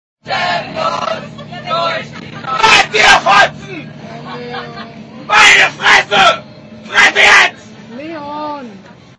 German Scream